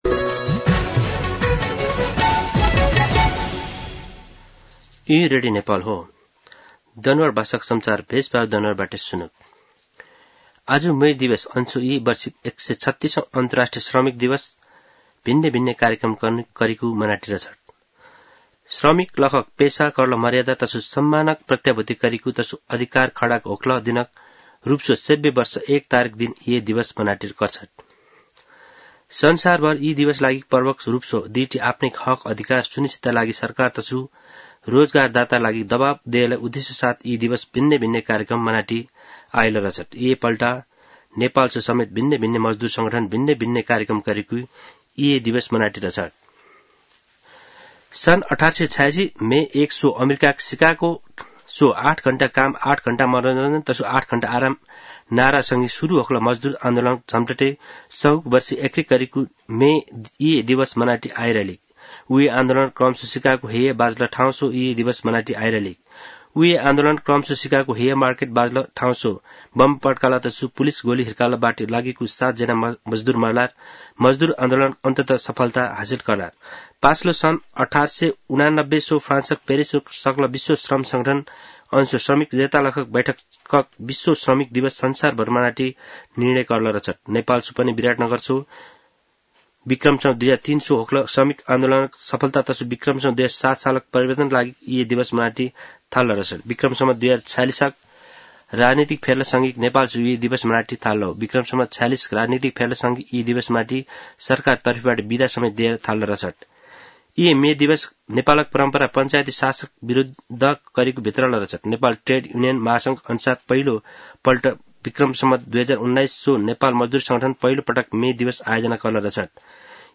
दनुवार भाषामा समाचार : १८ वैशाख , २०८२